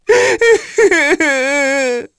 Neraxis-Vox_Sad_kr.wav